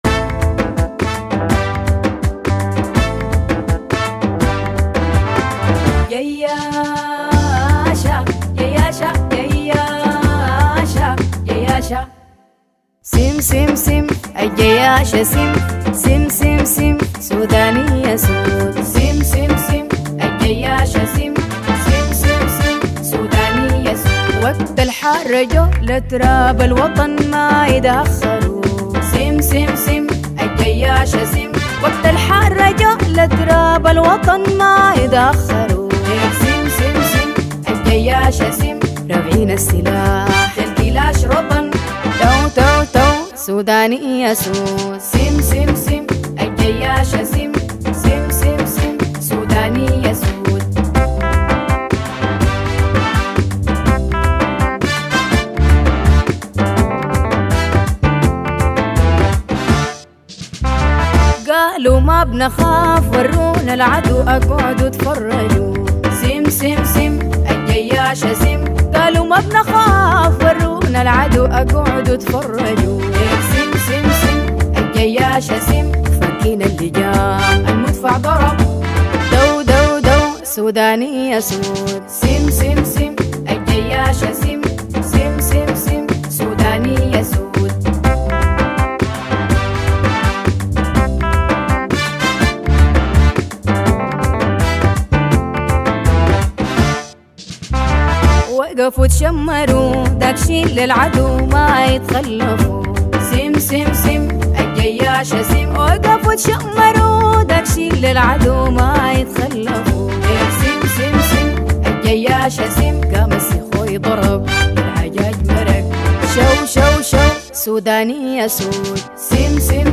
اغاني سودانية